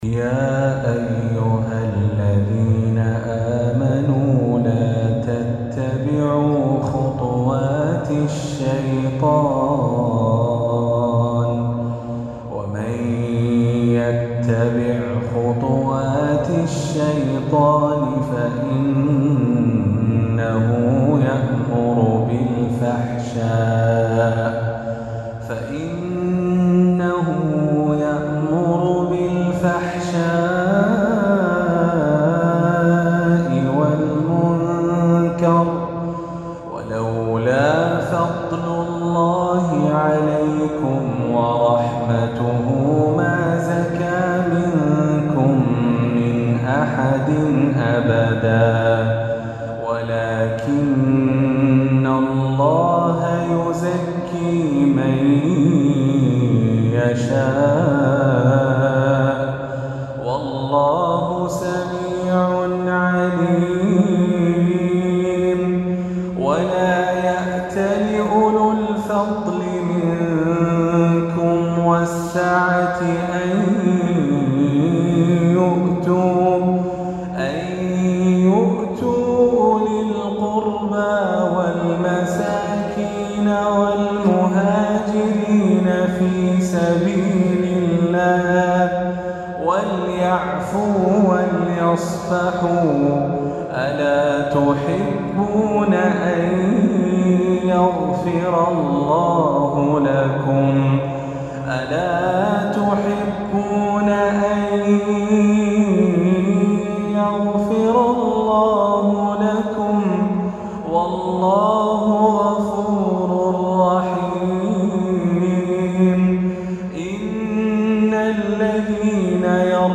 عشائية السبت